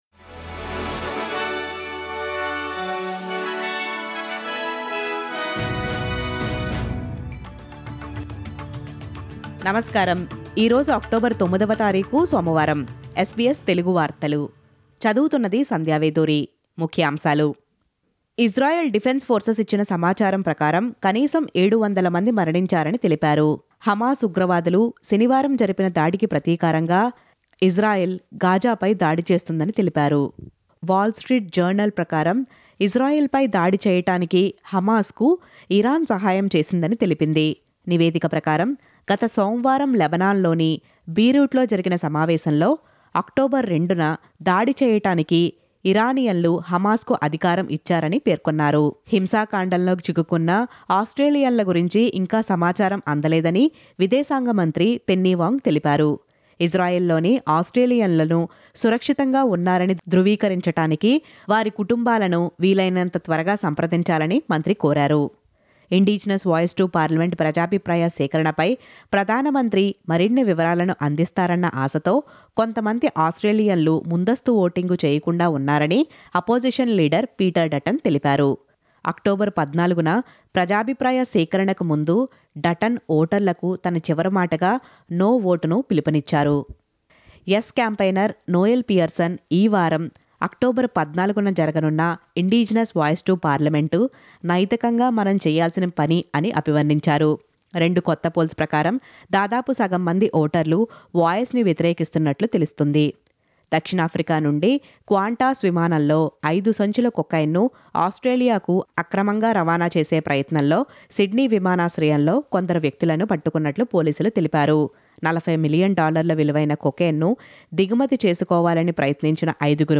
SBS తెలుగు 09/10/23 వార్తలు: మొబైల్ ఫోన్ ‌లపై నిషేధాన్ని అమలు చేస్తున్న NSW హైస్కూల్స్